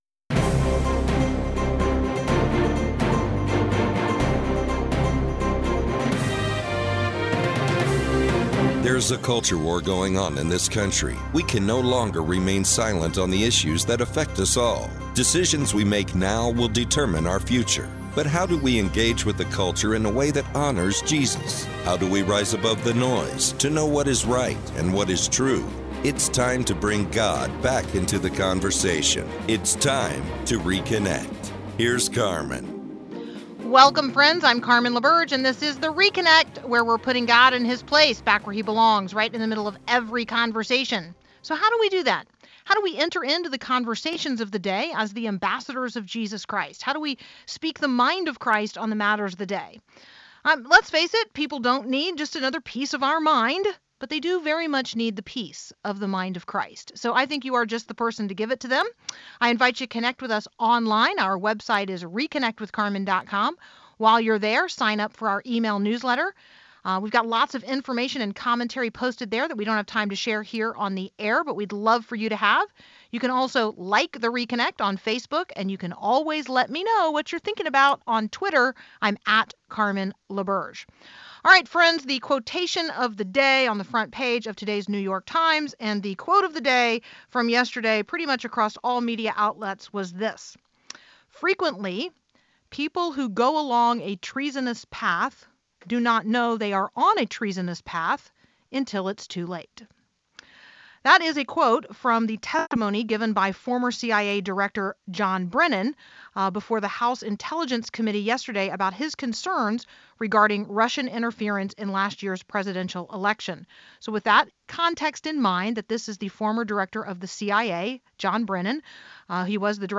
radio interviews.